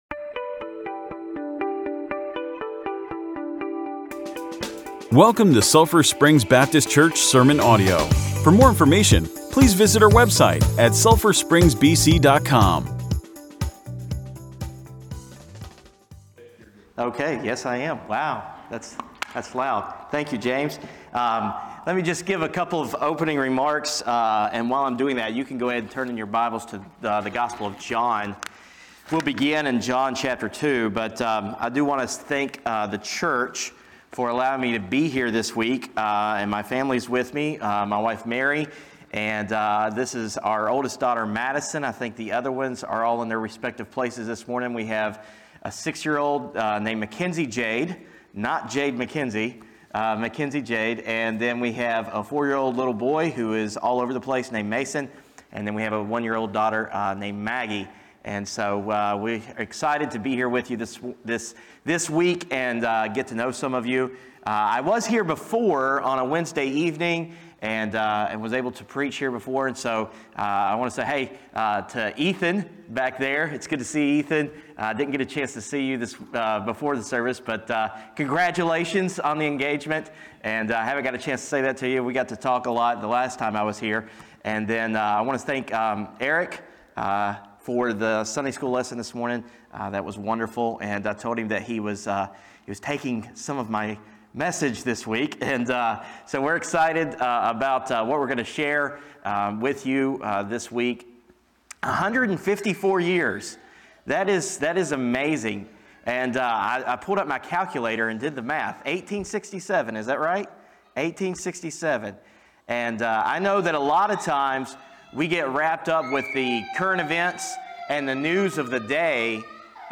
Sermons | Sulphur Springs Baptist Church
9-12-sermon.mp3